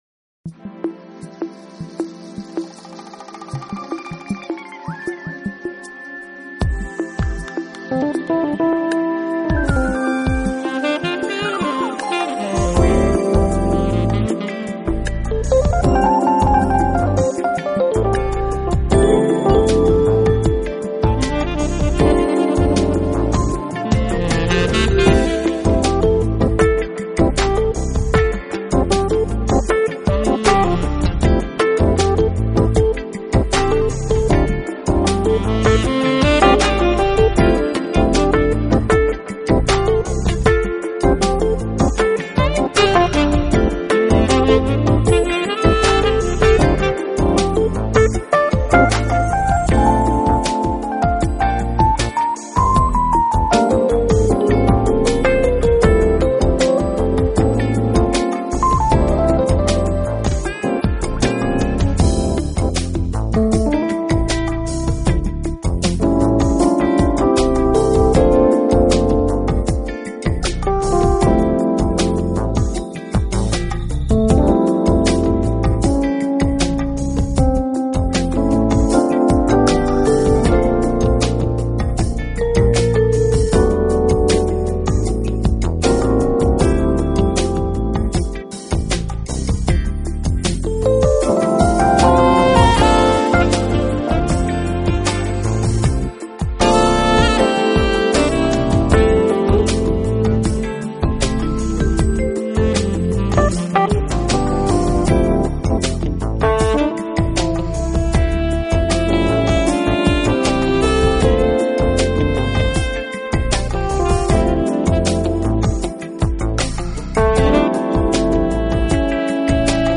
时而funky，时而blues，时而jazzy....犹如变换莫测的霓虹灯的映照下的五彩